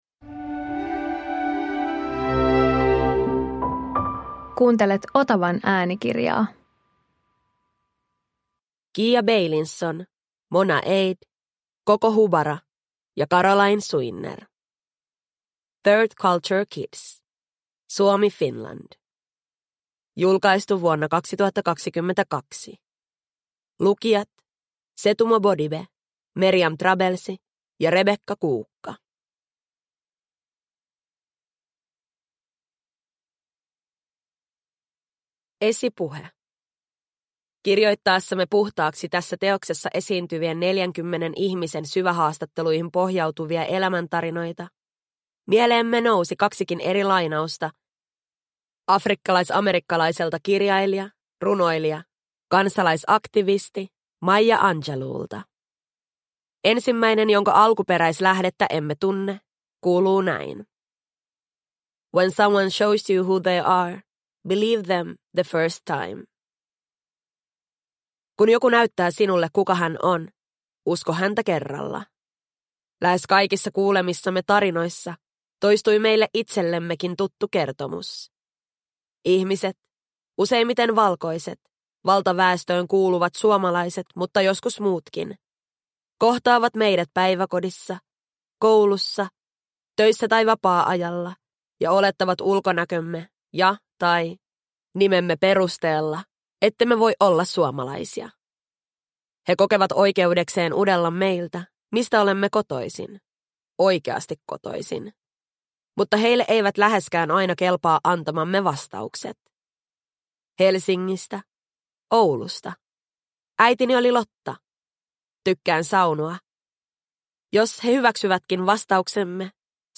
Third Culture Kids – Ljudbok – Laddas ner